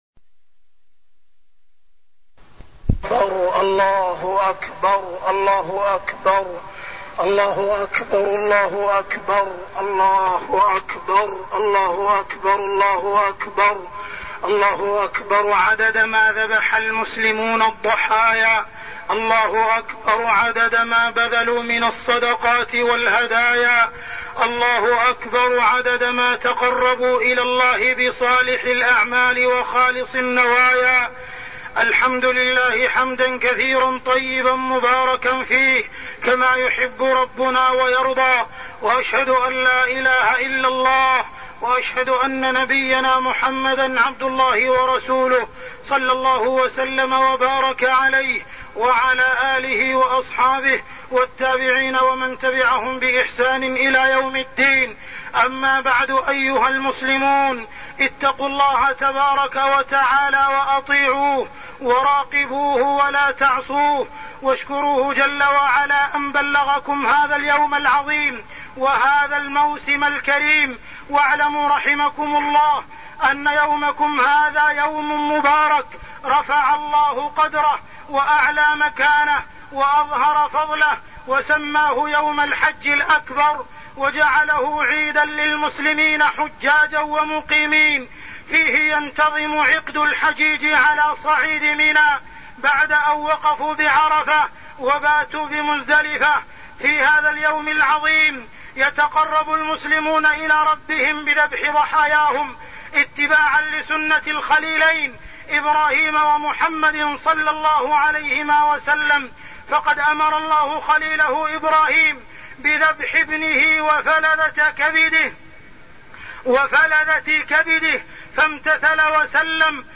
خطبة عيد الأضحى -هدي النبي في الأضحية
المكان: المسجد الحرام